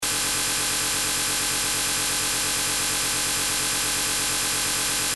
На этой странице собраны разнообразные звуки лазеров — от тонких высокочастотных писков до мощных энергетических залпов.
Звук непрерывного лазерного луча